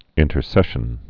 (ĭntər-sĕshən)